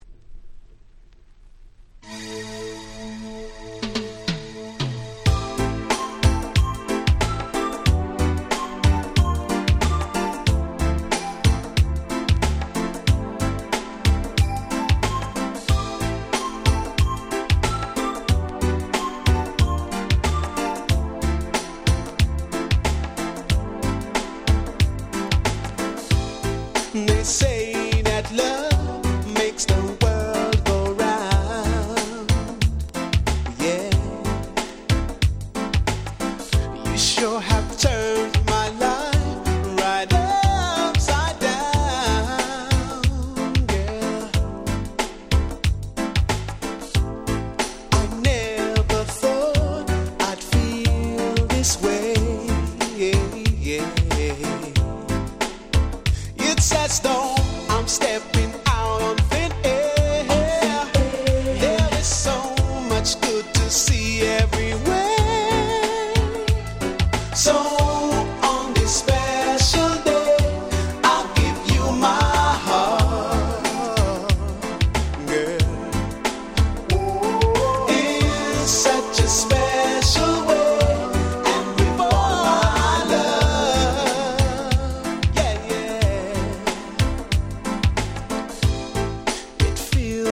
92' Very Nice UK R&B Album !!
甘い歌声が心地良いキャッチーなUK Soul / R&Bナンバーがてんこ盛りの良作です！！